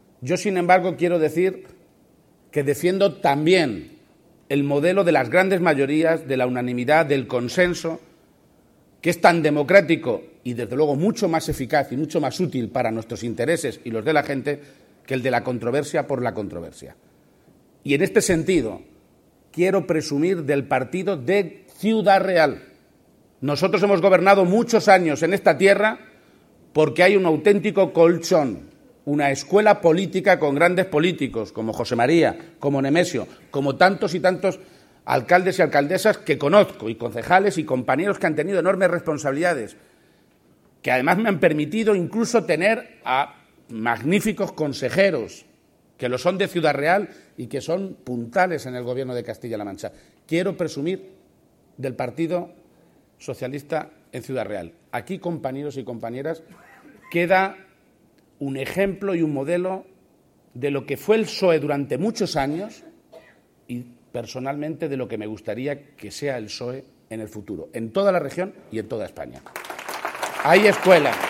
INAUGURACION DEL 17 CONGRESO PROVINCIAL DEL PSOE DE CIUDAD REAL
Cortes de audio de la rueda de prensa